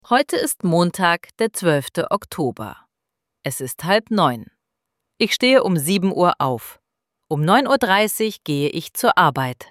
IZGOVOR – PRIMJERI:
ElevenLabs_Text_to_Speech_audio-51.mp3